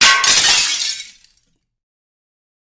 Звуки стекла
Треск разбитой вазы